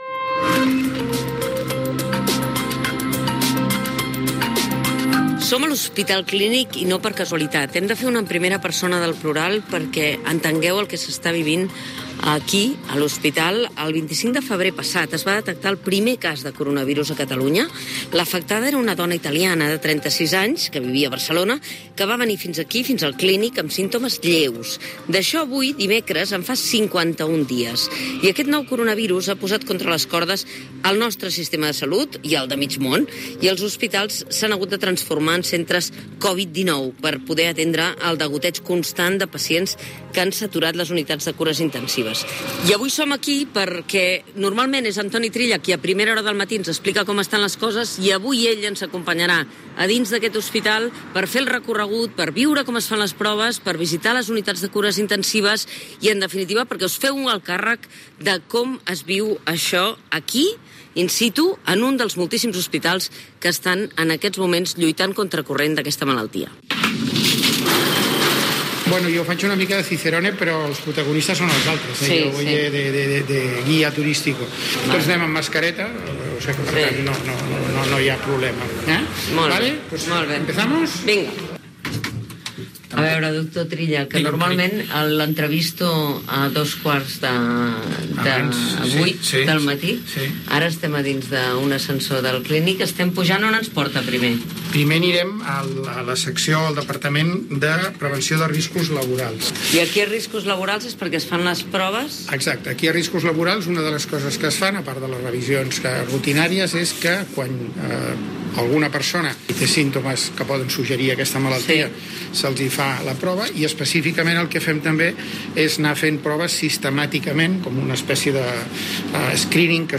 063479d0296bfd36fd55aeadd1d15023f7401b8b.mp3 Títol Catalunya Ràdio Emissora Catalunya Ràdio Cadena Catalunya Ràdio Titularitat Pública nacional Nom programa El matí de Catalunya Ràdio Descripció Reportatge fet a l'Hospital Clínic de Barcelona.
Info-entreteniment